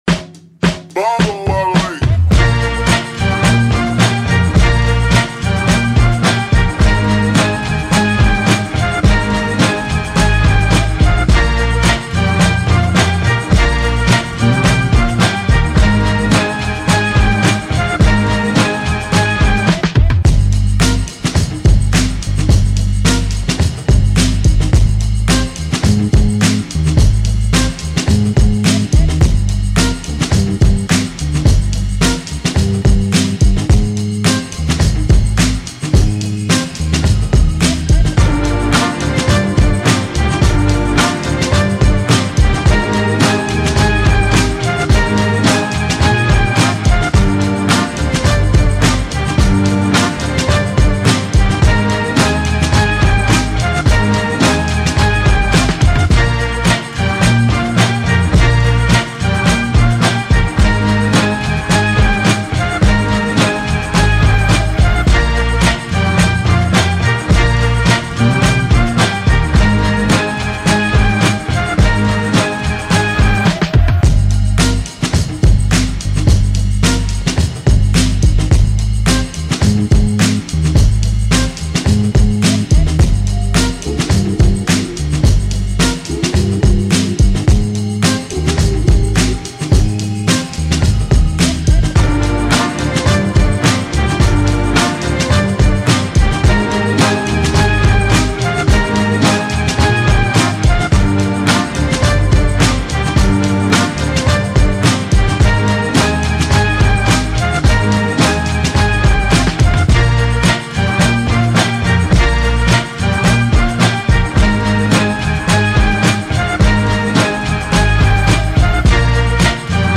official instrumental
R&B Instrumentals